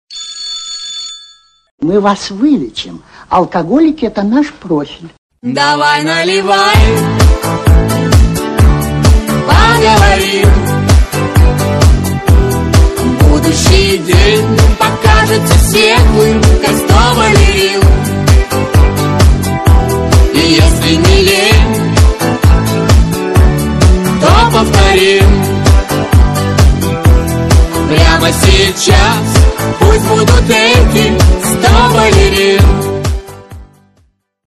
Танцевальные рингтоны
Поп